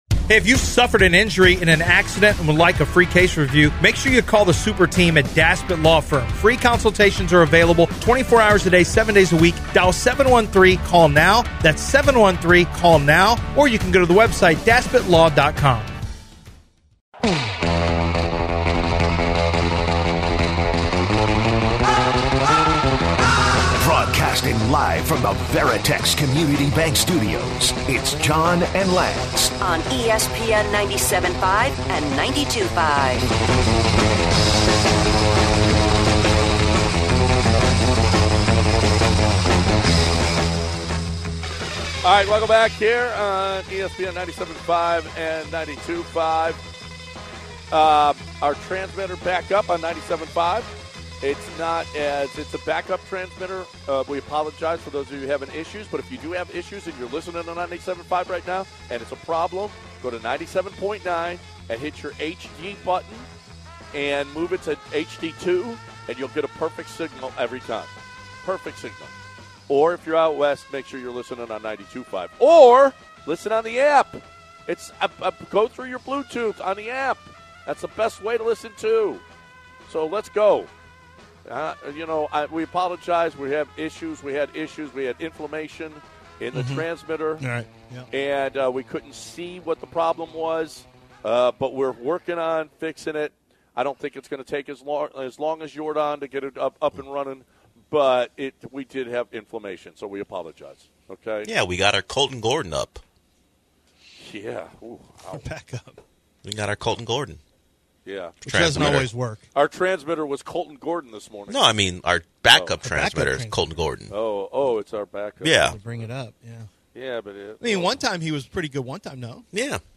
Who's to blame for the handling of Alvarez's injury? Is Kamari Lassiter the next breakout player for the Texans? And a special caller joins the show.